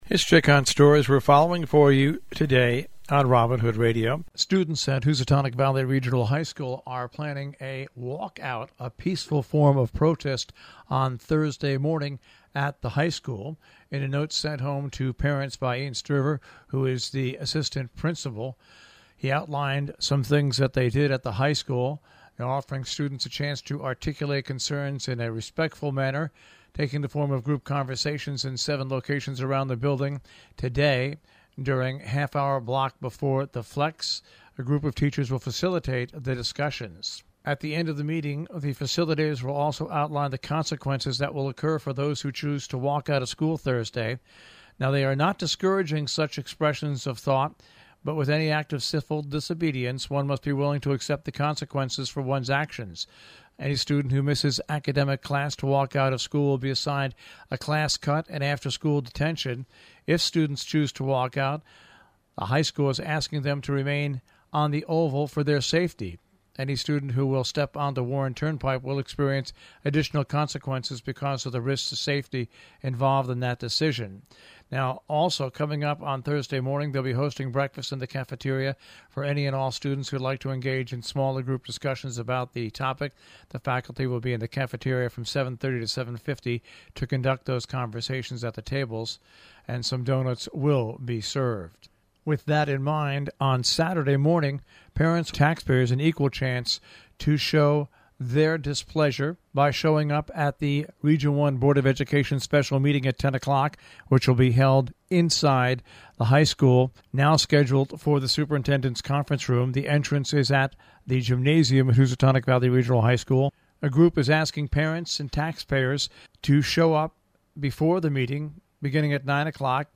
covers news and events in the Tri-State Region